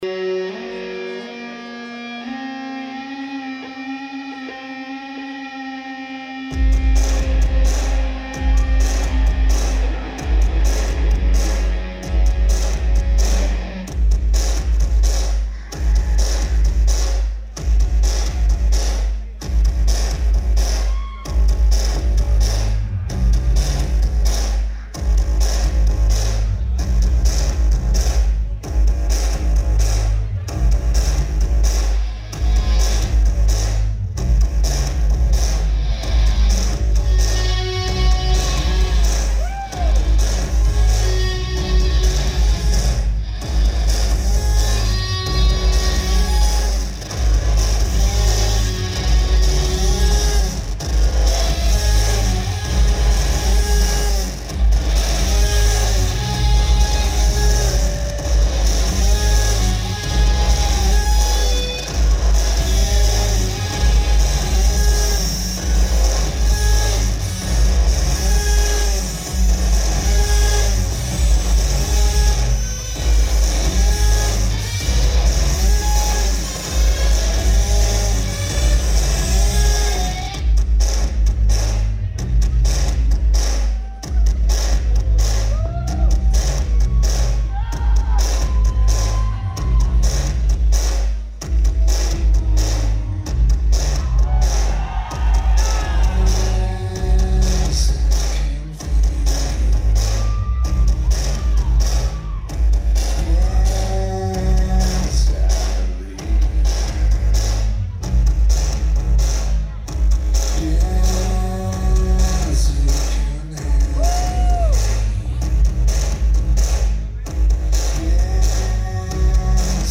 Vic Theatre
Chicago, IL United States
Synthesisers/Backing Vocals
Vocals/Guitar/Keyboards